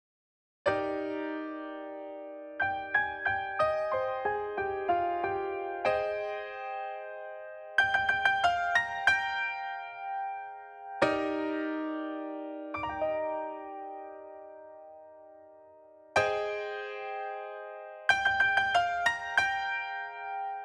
08 piano B.wav